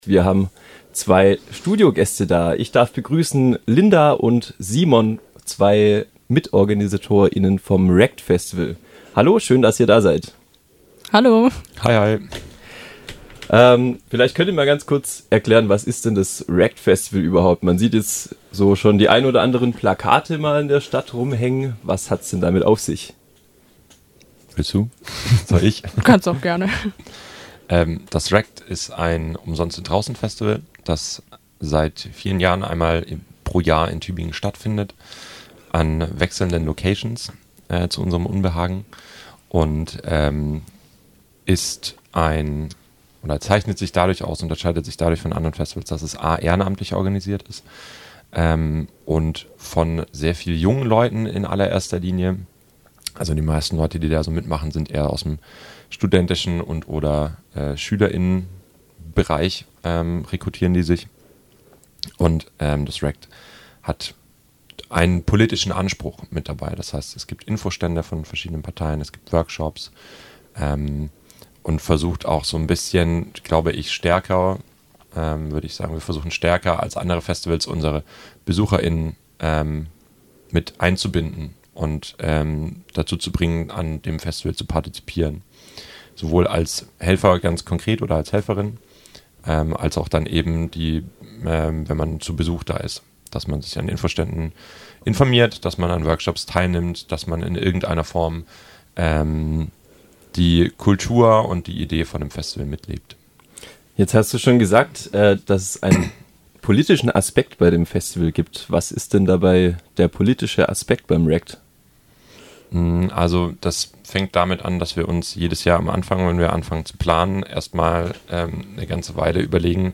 90731_Interview_Ract-_-_Festival_kurz.mp3